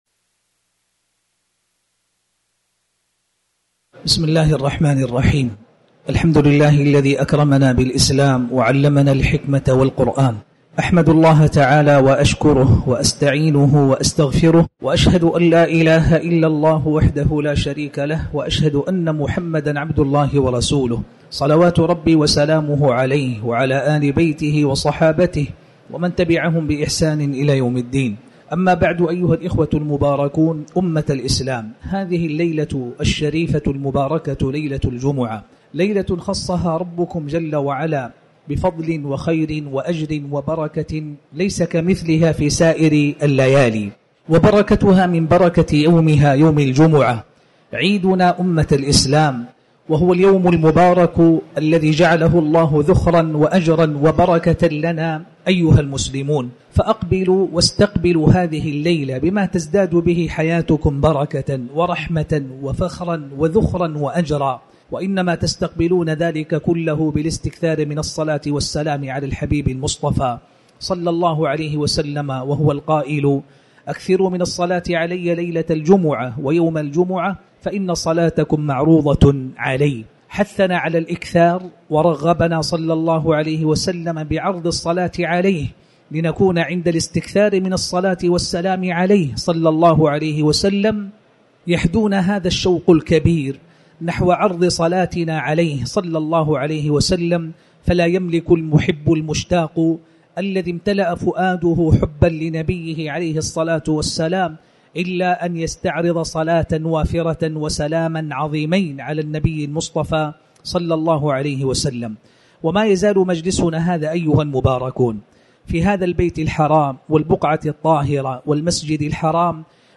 تاريخ النشر ٢٣ جمادى الآخرة ١٤٤٠ هـ المكان: المسجد الحرام الشيخ